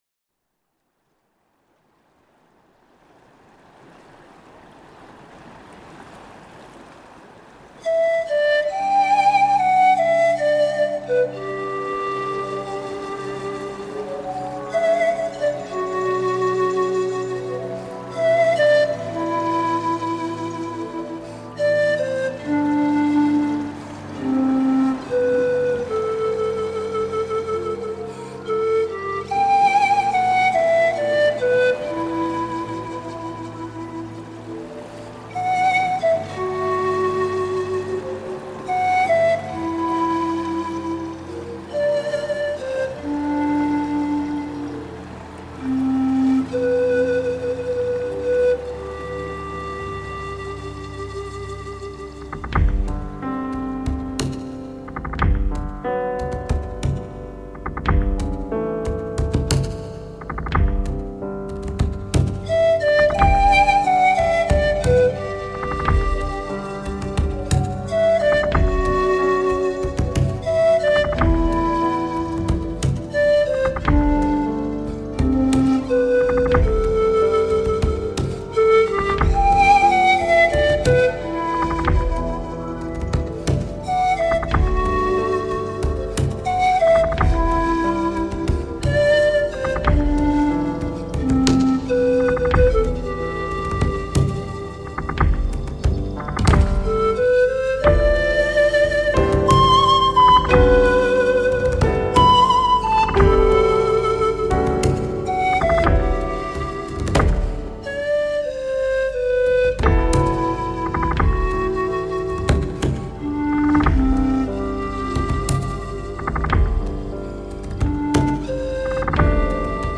新世纪风 发烧音碟